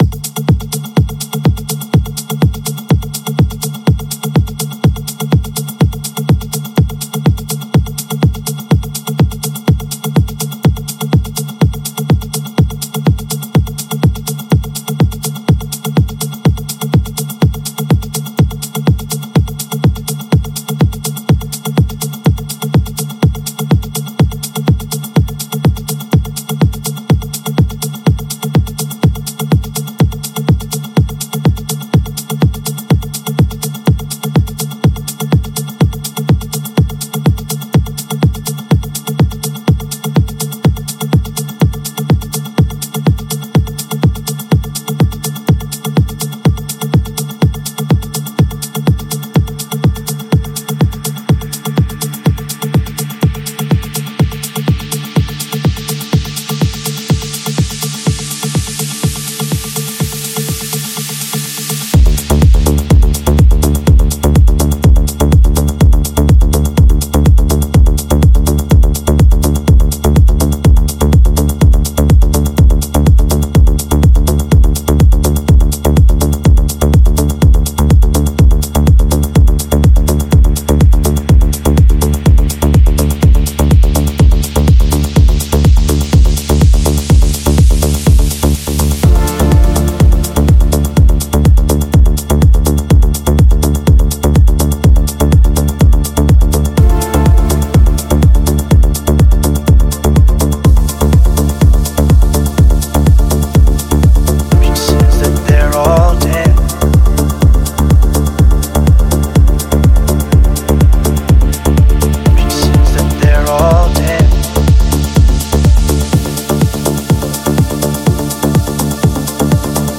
乐曲键：D分钟
体裁：旋律房屋